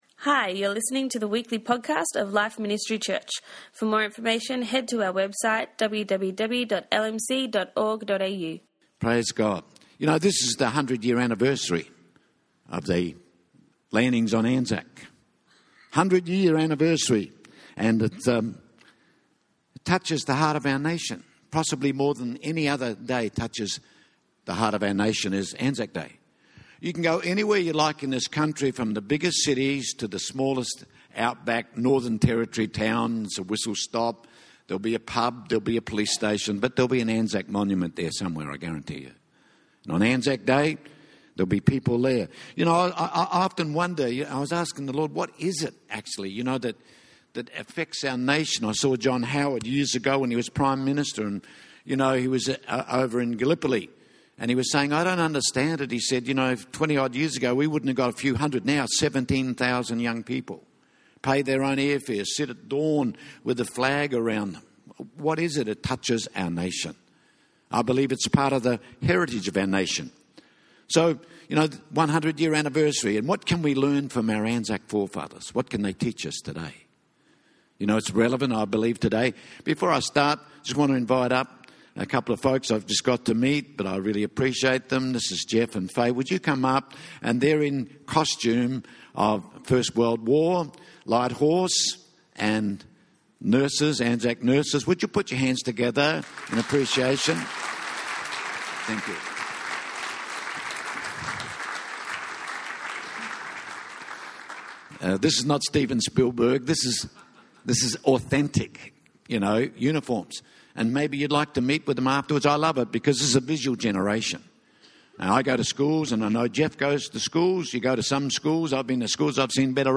Message by guest speaker